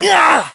hotshot_hurt_05.ogg